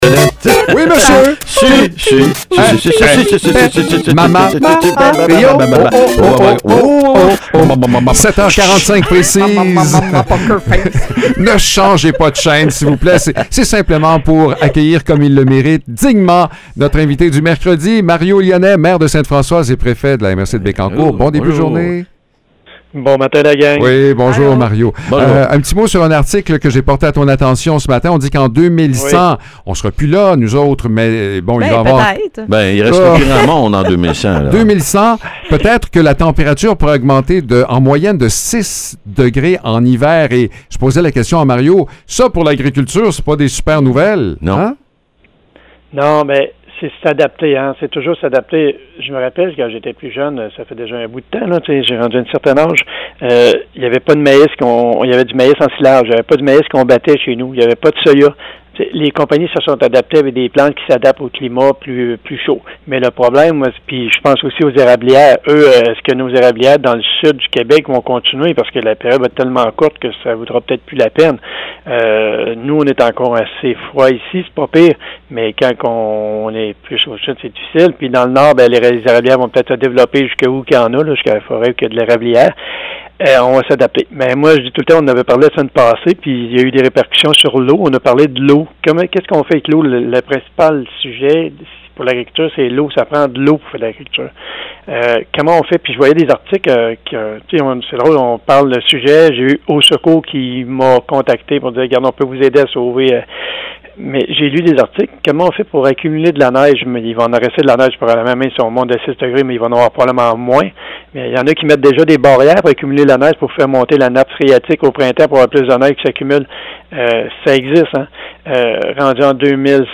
Mario Lyonnais, maire de Sainte-Françoise et préfet de la MRC de Bécancour, rappelle que des programmes de soutien financier sont disponibles à la MRC pour appuyer et encourager les projets culturels du milieu.